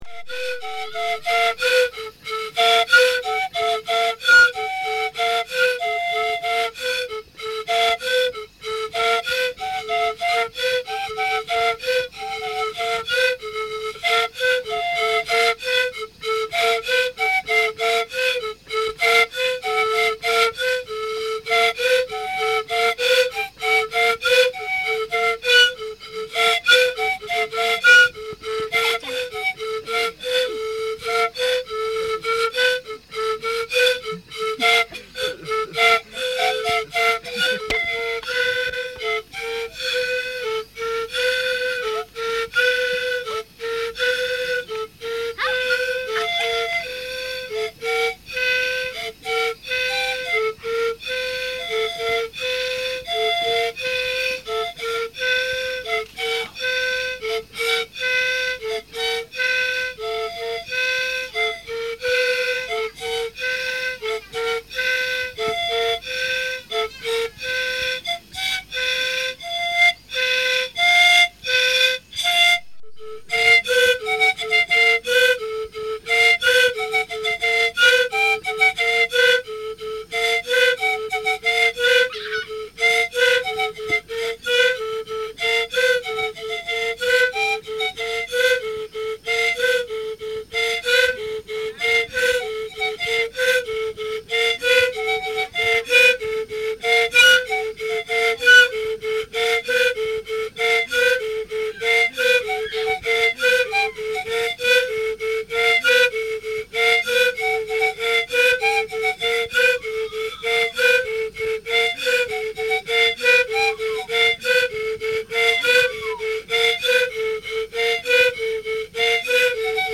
Chocó panpipe music
reel-to-reel tape recordings
in Colombia in 1965